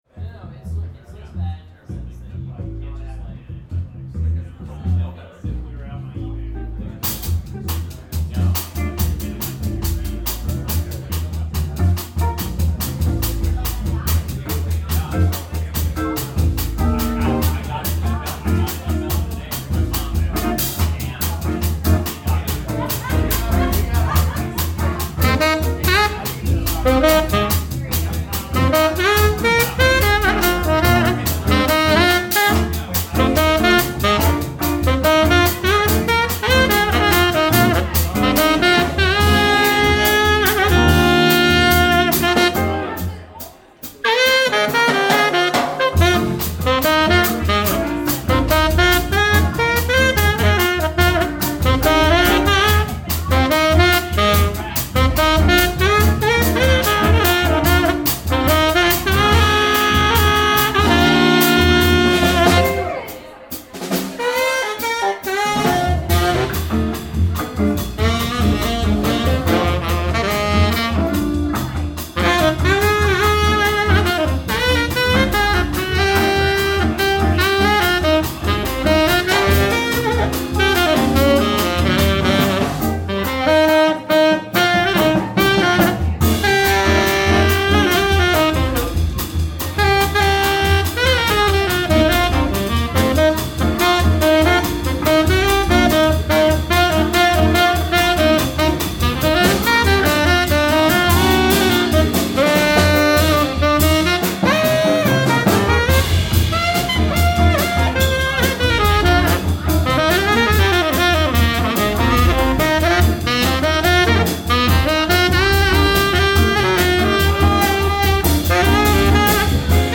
Tenor Sax
Guitar
Bass
Drums